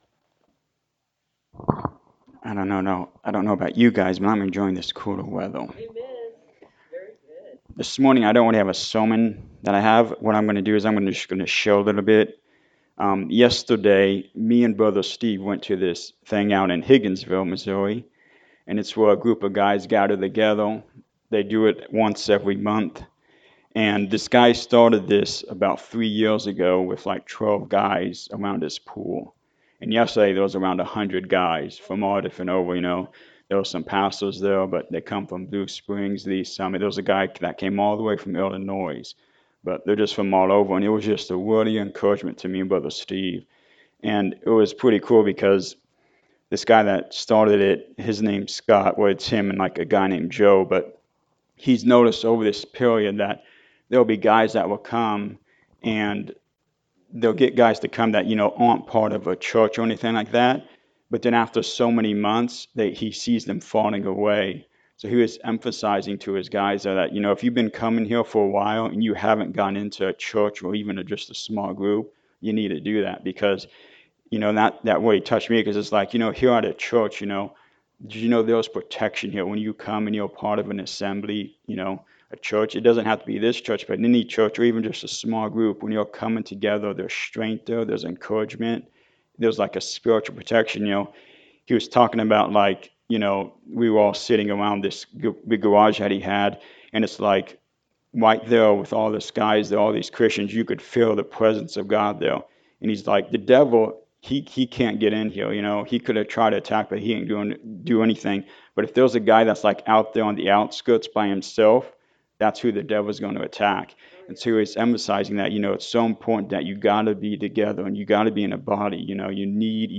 Luke 4:18-19 Service Type: Sunday Morning Service Painful emotions from hurts are hard to deal with.
Sunday-Sermon-for-September-8-2024.mp3